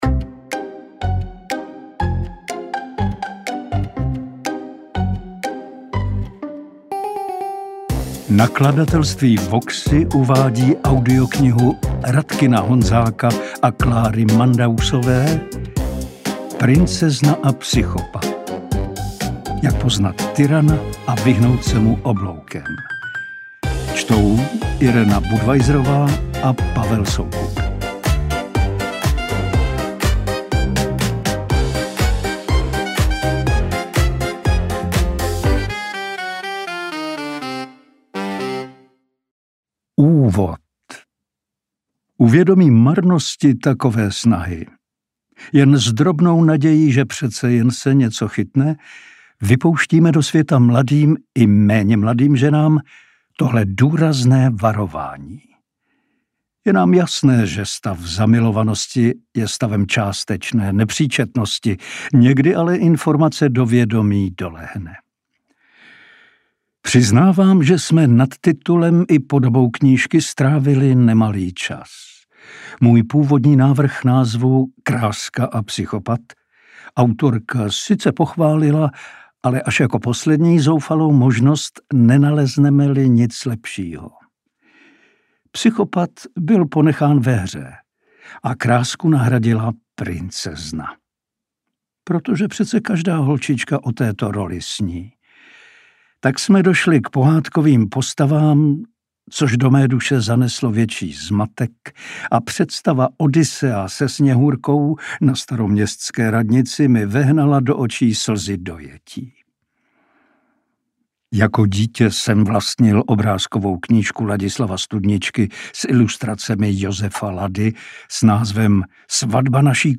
Interpreti:  Irena Budweiserová, Pavel Soukup